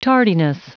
Prononciation du mot tardiness en anglais (fichier audio)
Prononciation du mot : tardiness